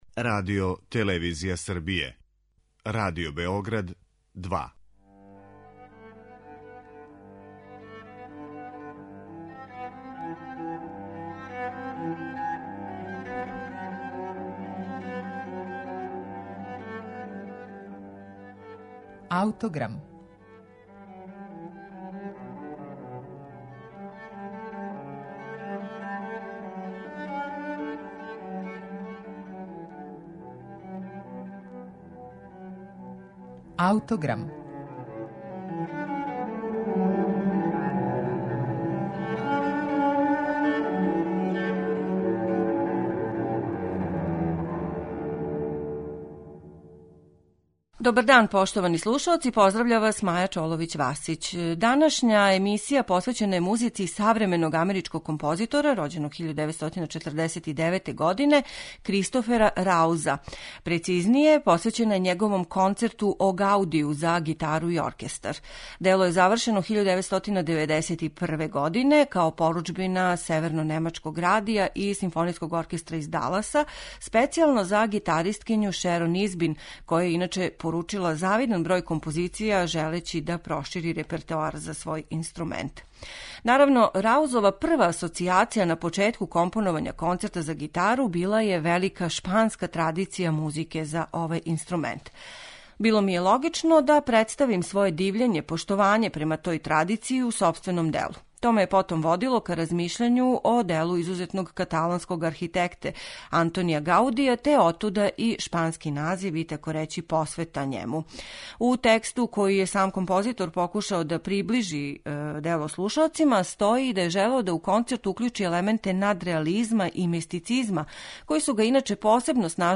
Овај савремени амерички композитор је свој Концерт за гитару и оркестар написао 1991. године за Шерон Избин, као поруџбину Севернонемачког радија и СО из Даласа.